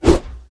swing2.wav